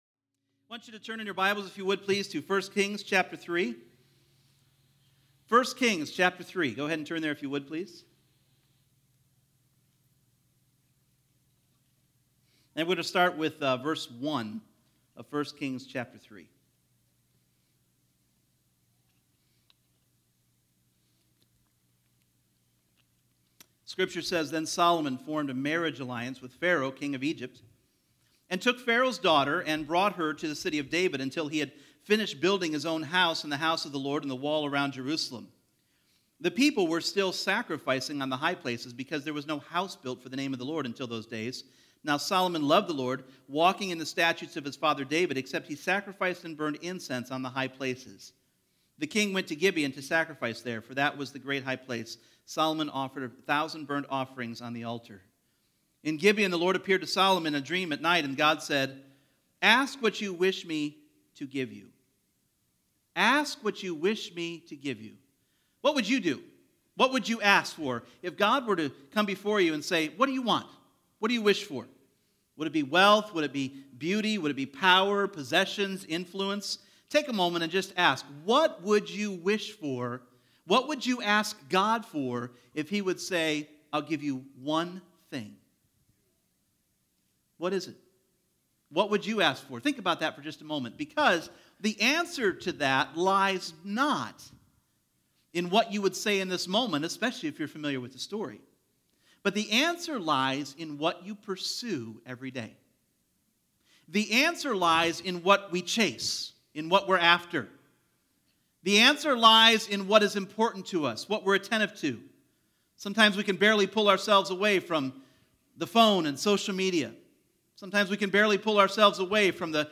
Individual Messages Service Type: Sunday Morning There are two types of wisdom.